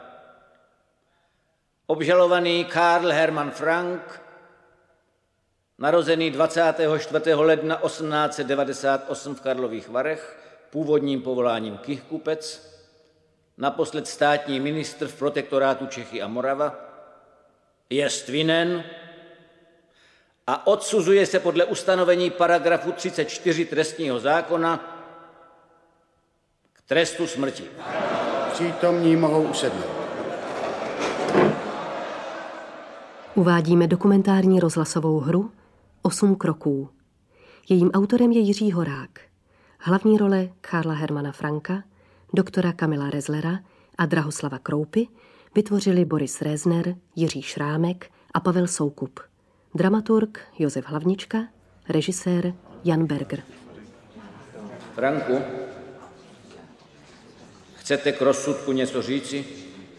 Audiobook
Audiobooks » Short Stories
Read: Jaromír Meduna